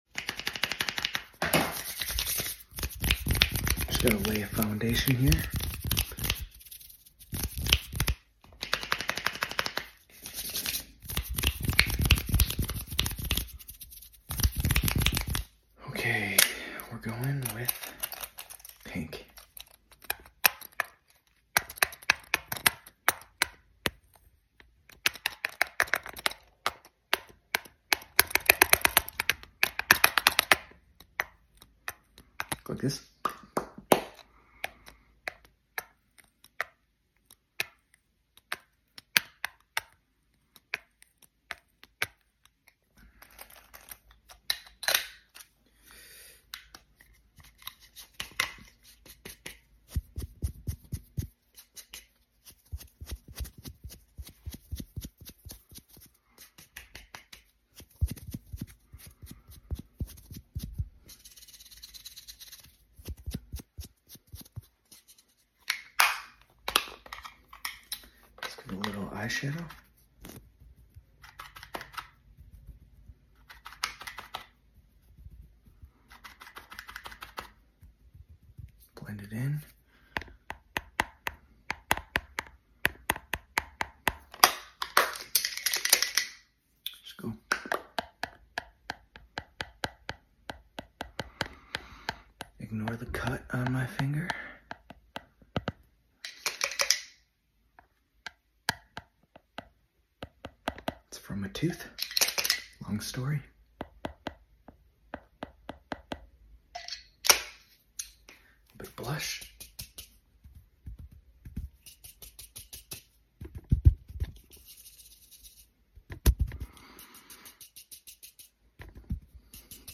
ASMR | Doing Your Makeup sound effects free download